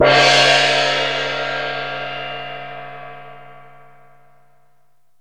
Index of /90_sSampleCDs/Roland LCDP03 Orchestral Perc/CYM_Gongs/CYM_Gongs Dry
CYM SMLGONG.wav